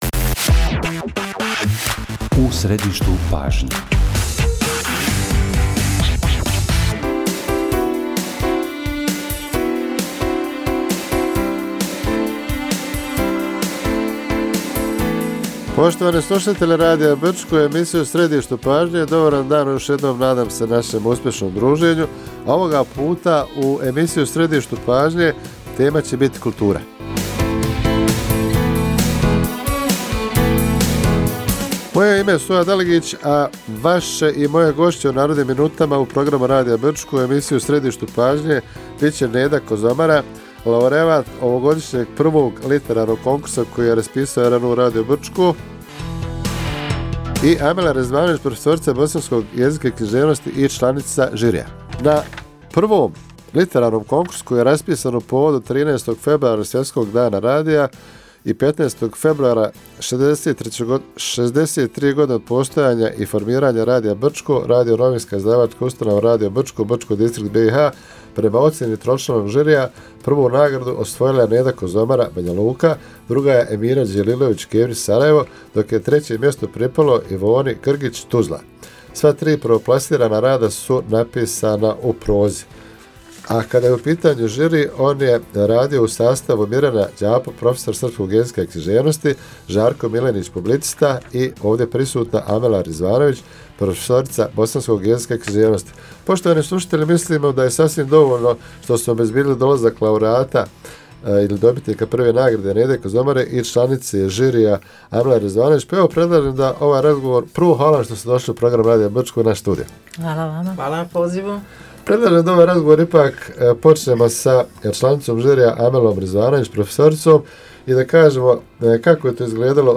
Emisija sa pobjednicom literarnog konkursa